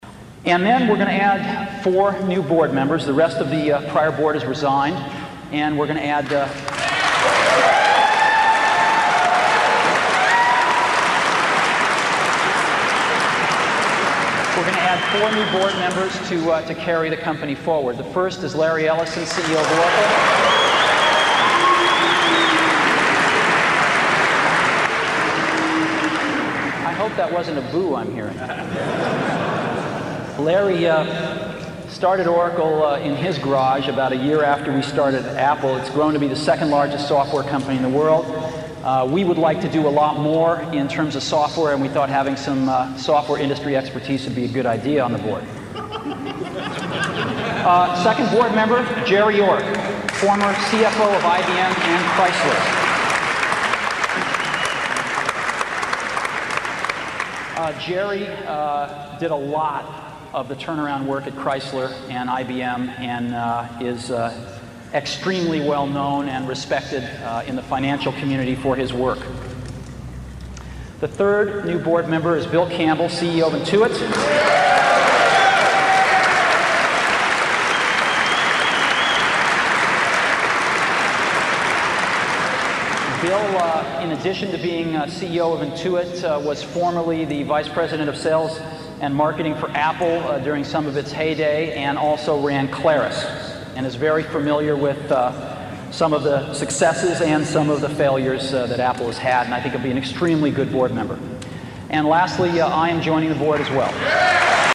财富精英励志演讲84:在疯狂中我们看到了天才(4) 听力文件下载—在线英语听力室